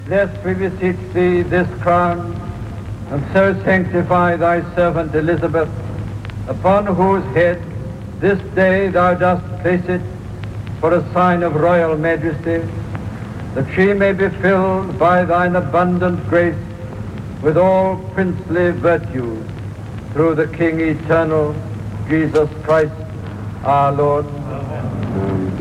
Retransmissió de la coronació de la reina Isabel II d'Anglaterra. Paraules de l'arquebisbe de Canterbury, Geoffrey Fisher.
Informatiu